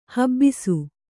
♪ habbisu